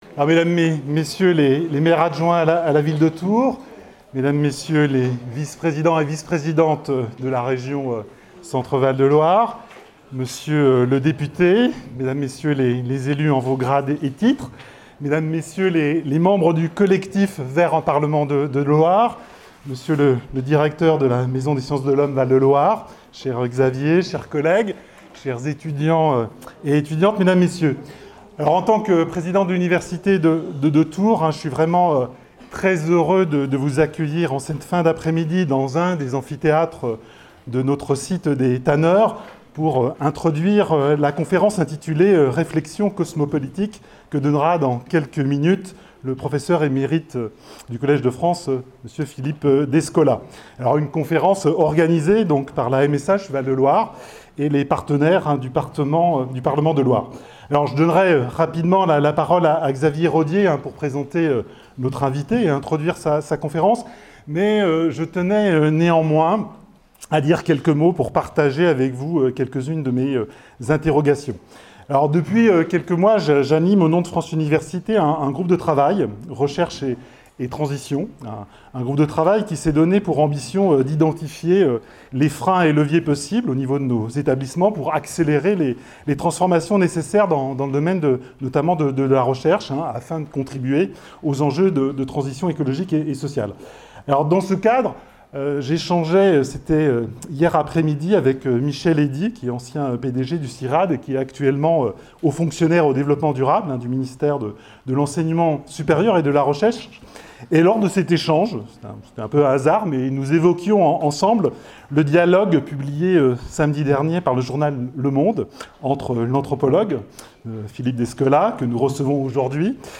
La MSH Val de Loire et le collectif vers un parlement de Loire ont reçu l’anthropologue Philippe Descola, professeur émérite du Collège de France, le 16 juin 2023 à Tours.